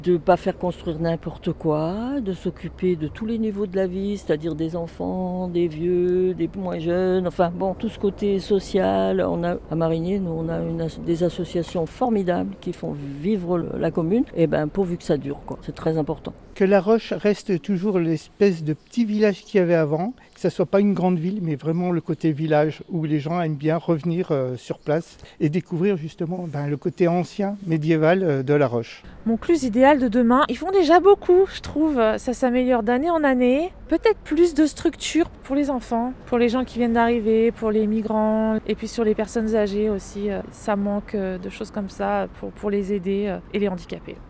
La Rédaction est allée vous poser la question dans la Vallée de l'Arve.